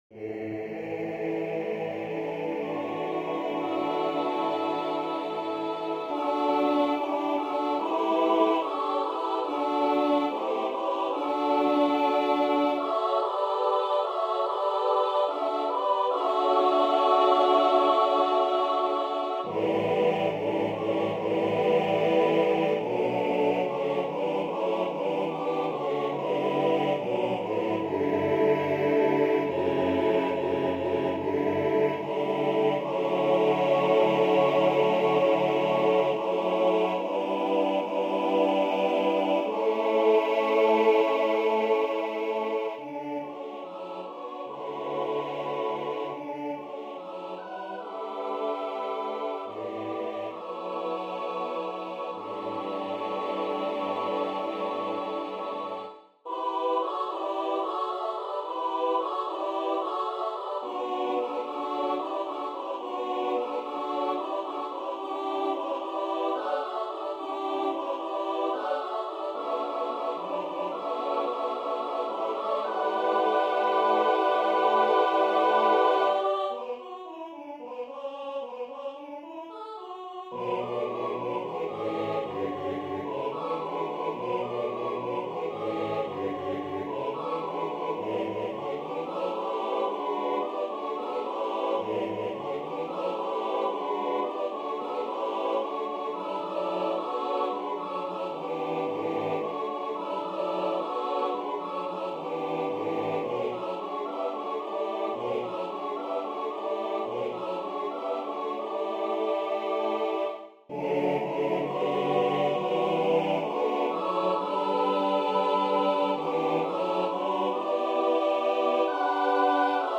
SATB div.